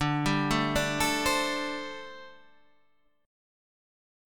D7sus4 chord